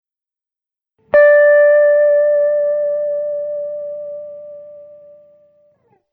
wibrato 2
Polega on na swego rodzaju "turlaniu" palca wzdłuż gryfu bez podciągania struny:
Z drugiej jednak strony dzięki temu wibrato to daje efekt bardziej subtelny niż wibrato wykorzystujące podciąganie struny.
wibrato_2.mp3